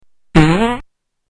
FX - Fart